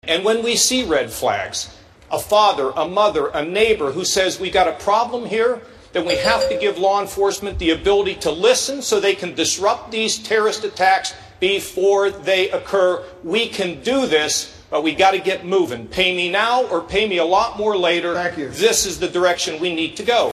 Ohio Gov. John Kasich toned down his attacks on rival Donald Trump to focus on terrorism and climate change during last night's GOP debate in Las Vegas. Kasich called out rivals for “fighting and arguing” instead of uniting people to solve problems. He called for a coalition and swift action to fight ISIS.